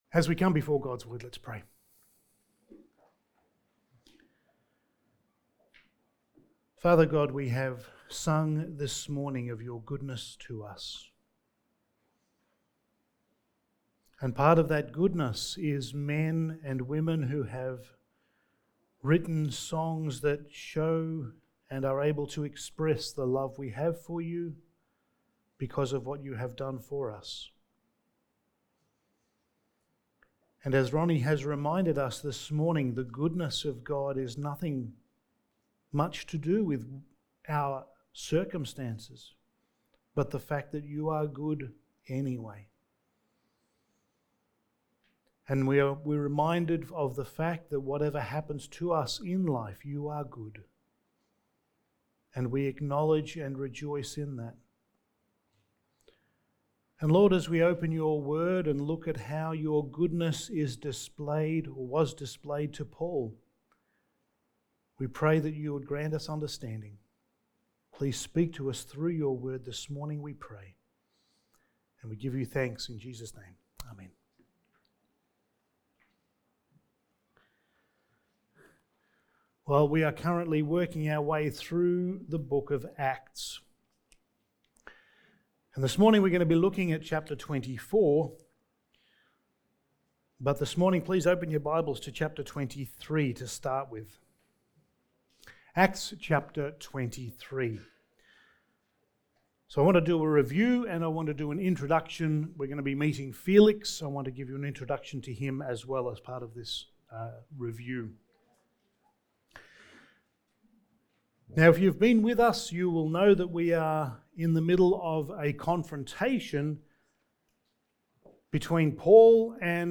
Passage: Acts 24:1-27 Service Type: Sunday Morning